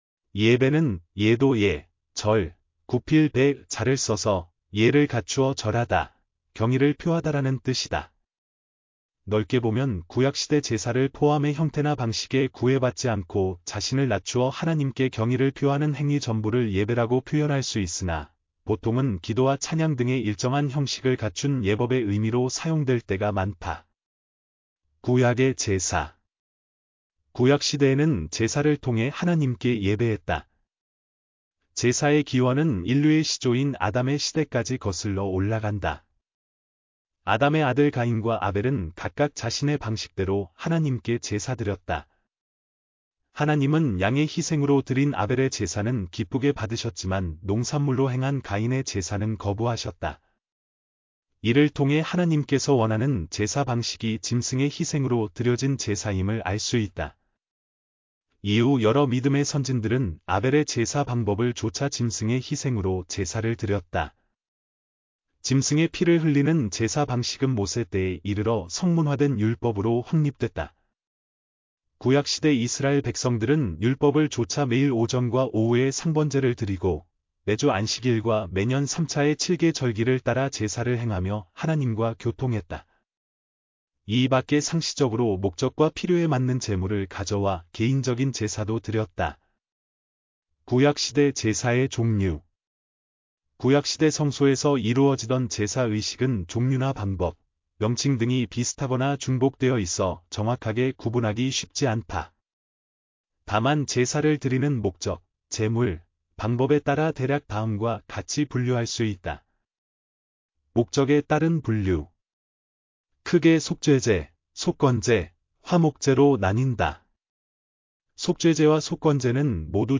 예배.mp3